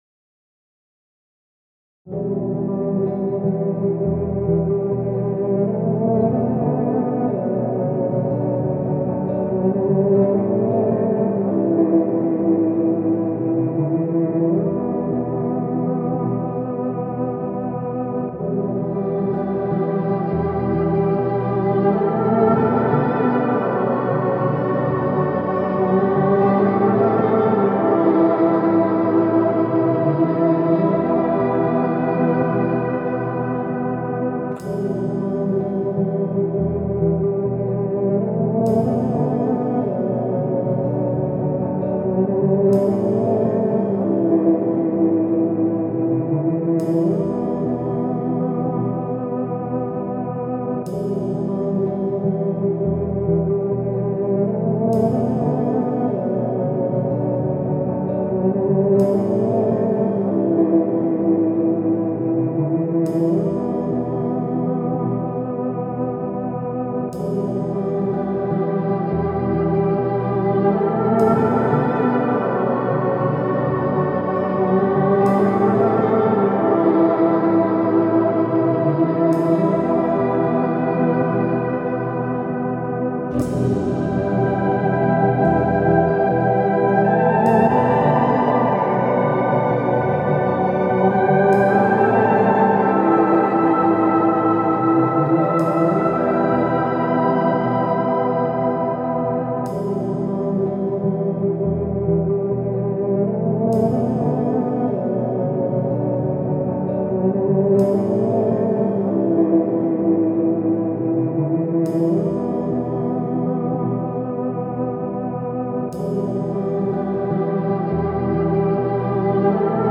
بیت رپ
بیت خالی